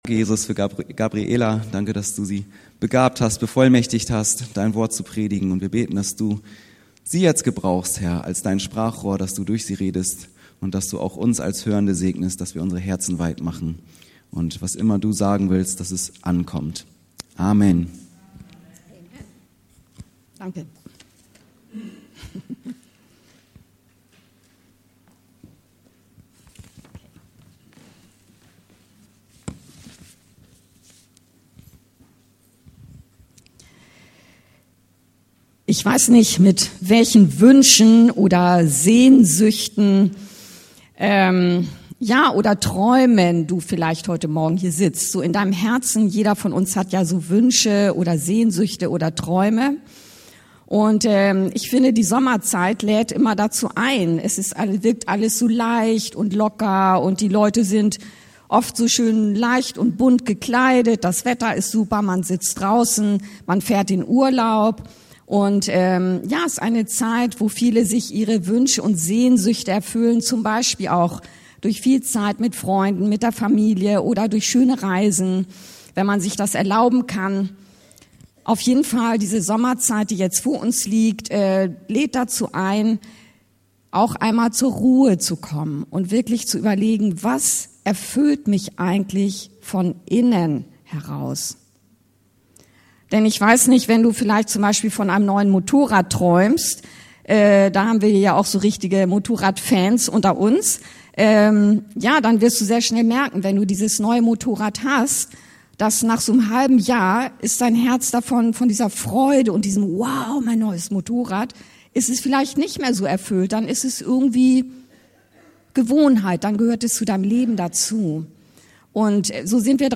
Er-füllt Leben heißt reiche Frucht bringen! (Joh. 15,1-17) ~ Anskar-Kirche Hamburg- Predigten Podcast